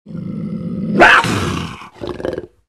Звуки пумы
Зловещий рык в глубине джунглей